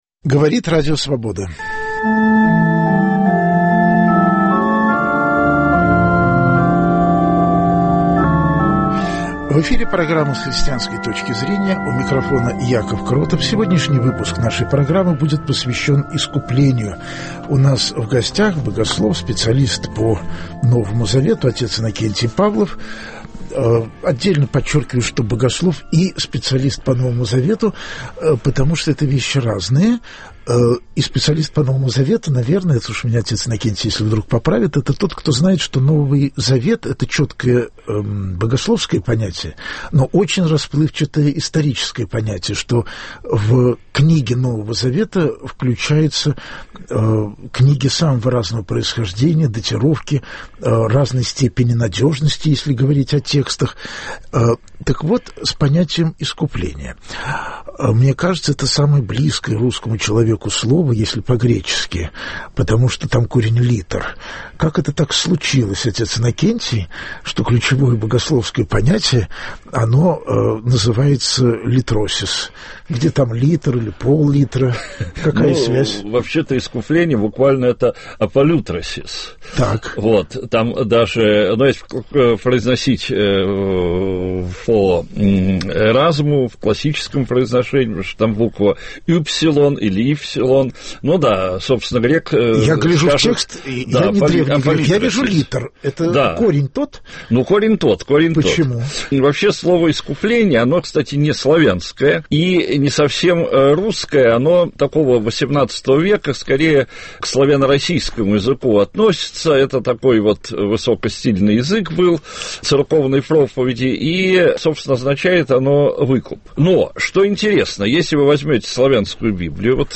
Когда христиане говорят об искуплении, означает ли это, что Бог перевёл Христа тёмным силам, чтобы отмыть человечество? О символике искупления разговор